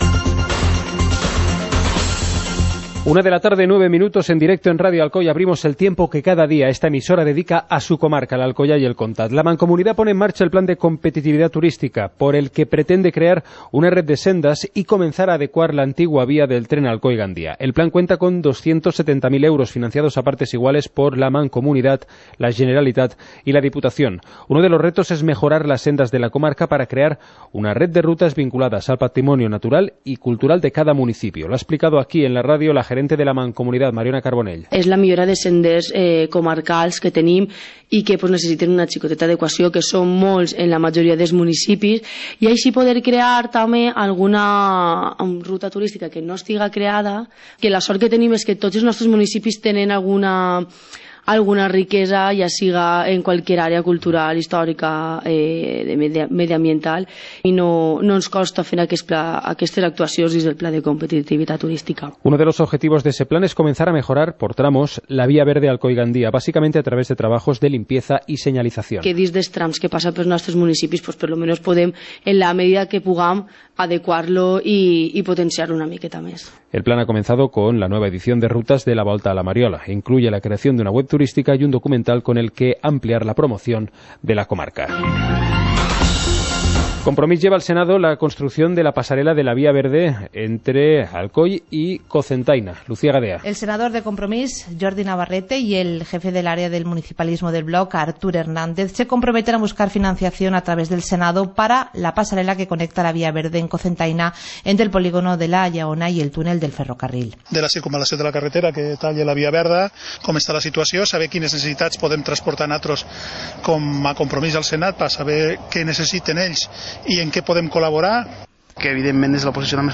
Informativo comarcal - miércoles, 12 de abril de 2017